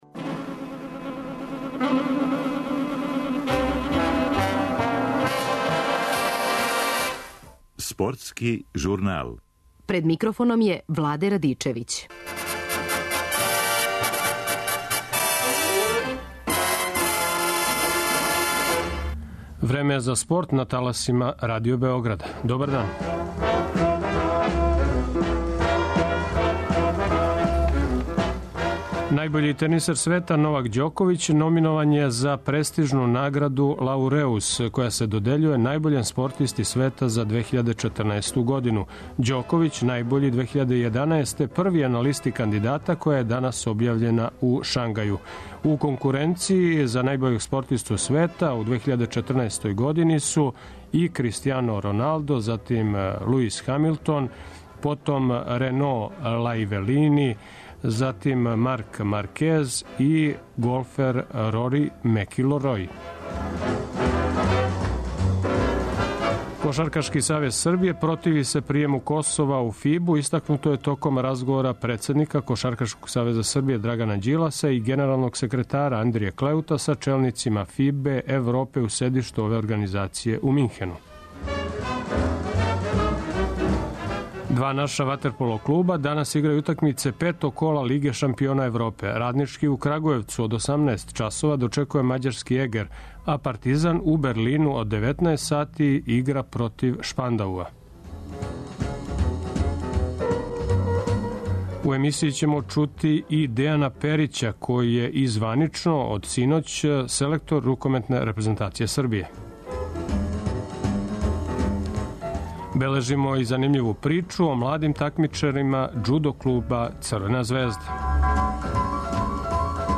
У емсији говори и званично нови селектор рукометне репрезентације Србије Дејан Перић.